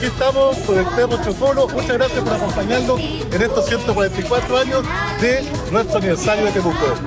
Roberto-Neira-alcalde-de-Temuo-.mp3